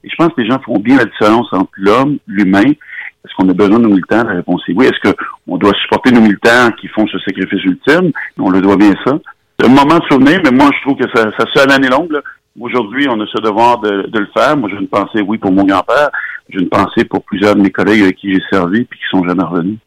C’est ce qu’il a déclaré sur les ondes du FM 103,3 ce vendredi, en chemin vers la Place du Canada à Ottawa pour la commémoration officielle de l’événement.